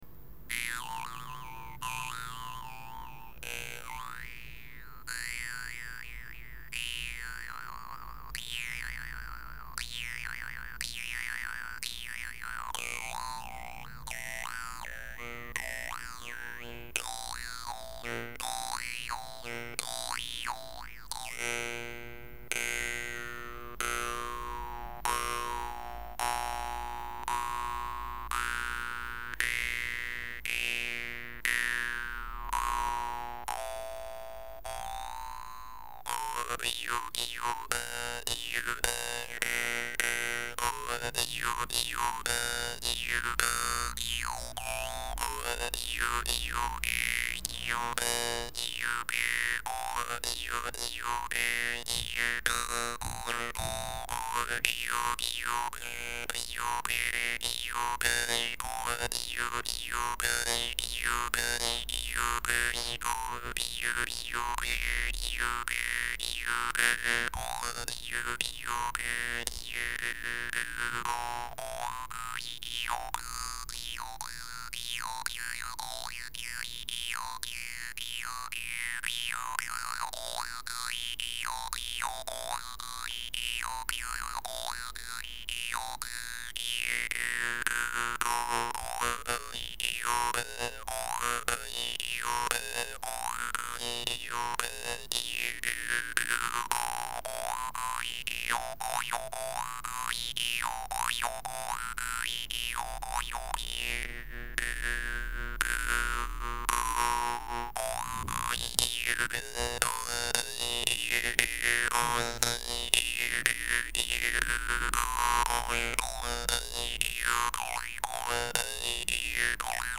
compos guimbardes laos
Sinon, la compo, j'aime assez: bien rythmé, quelques bonnes sonorités... Le "touché" sur la languette me semble parfois un peu trop appuyé, mais bon, c'est juste pour chipotter...
Ouai globalement elle est agréable à écouter cette compo, c'est bien rythmé et pas trop mal enregistré.
En revanche c'est vrai que ça laisse un peu un sentiment de jeu bourrin, sans trop de nuances.
La compo est bien sympa à écouter, enregistrement nickel, rythme sympa et pas mal d'harmo... du tout bon !